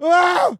scream_short_2.ogg